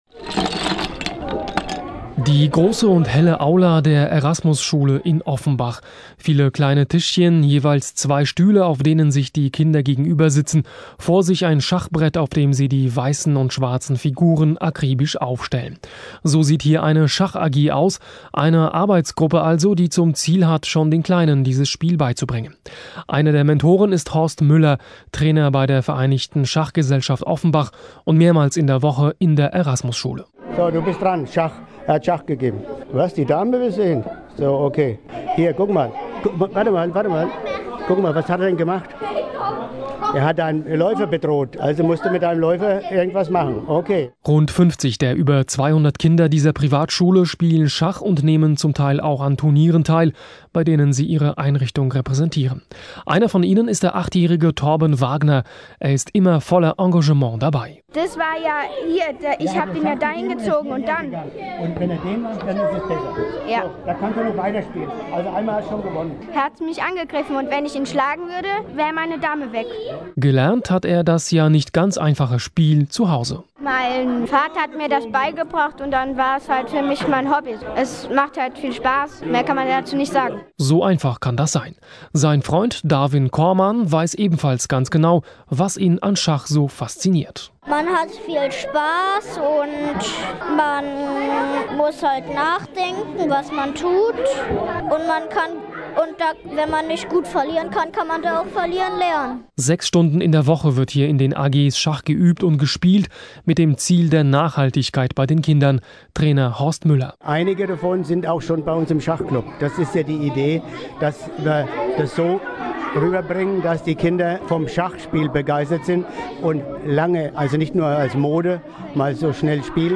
Anlässlich der Verleihung des Qualitätssiegels „Deutsche Schachschule“ an die Erasmus-Grundschule berichtet der HR im Radio mehrfach.